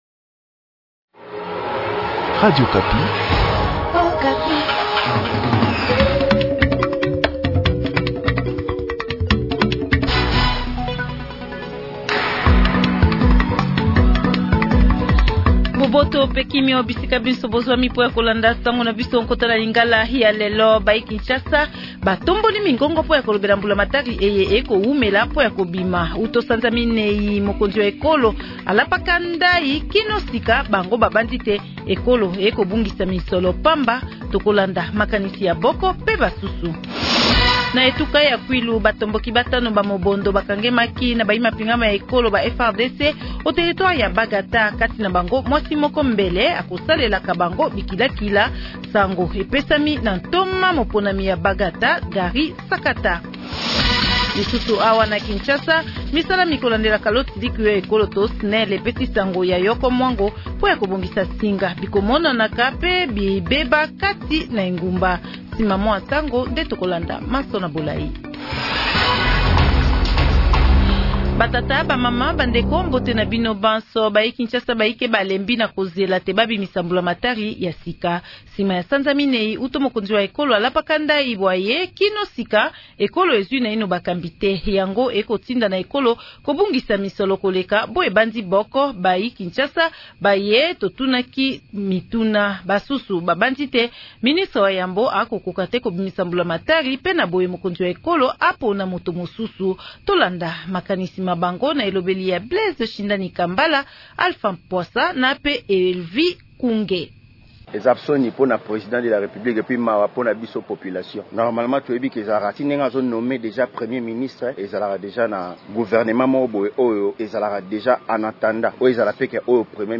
Journal Lingala Matin